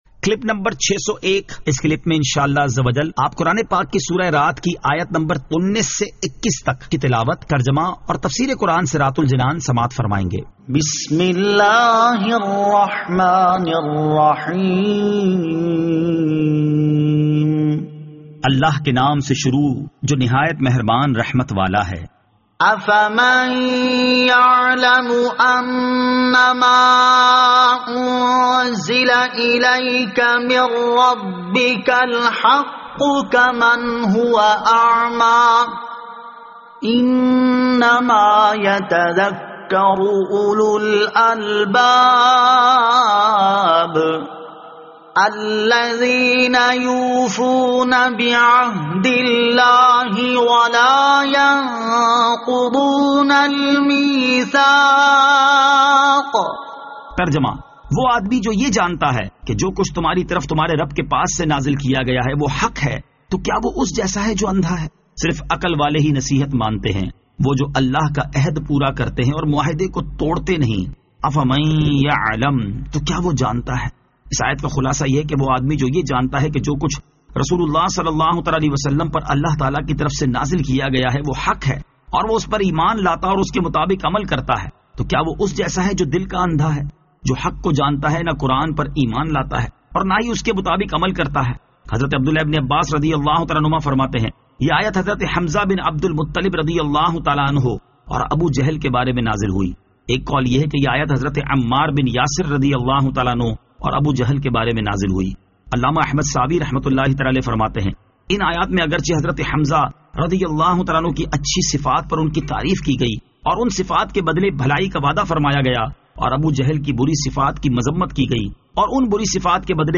Surah Ar-Rad Ayat 19 To 21 Tilawat , Tarjama , Tafseer
2021 MP3 MP4 MP4 Share سُوَّرۃُ الرَّعٗد آیت 19 تا 21 تلاوت ، ترجمہ ، تفسیر ۔